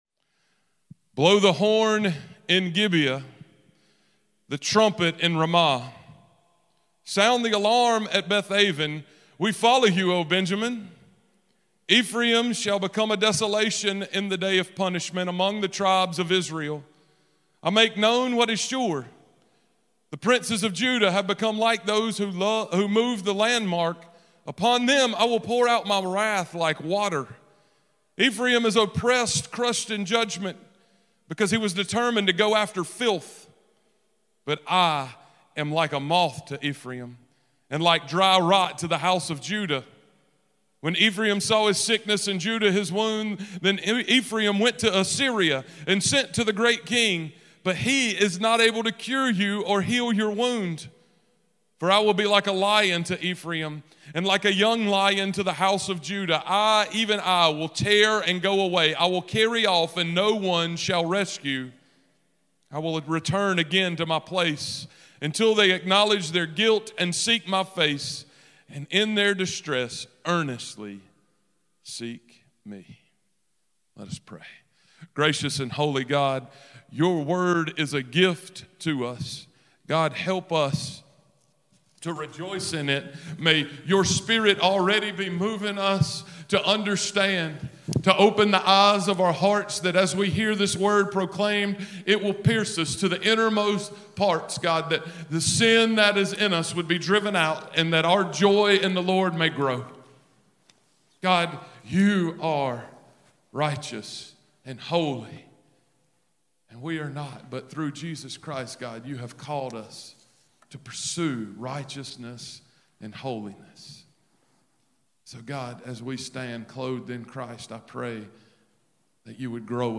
Sermon-3-20-23.mp3